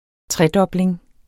Udtale [ ˈtʁεdʌbleŋ ]